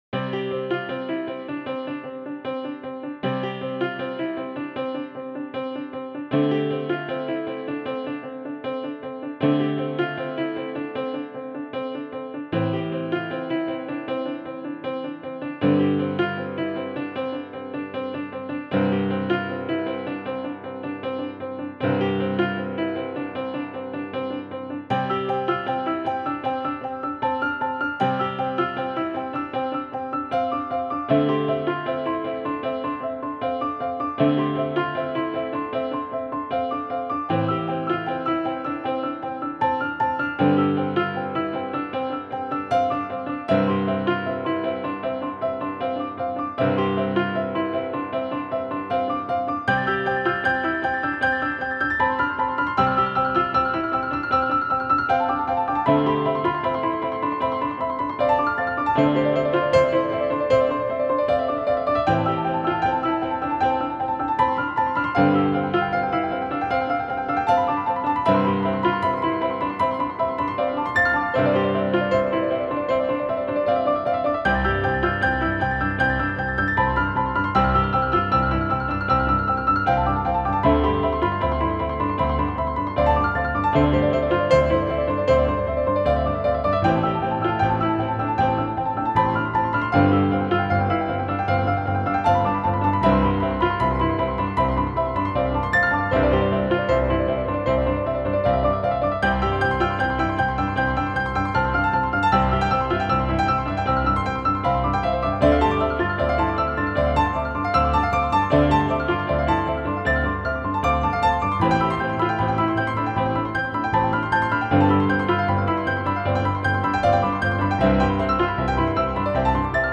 One humble voice multiplies into an ecstatic ensemble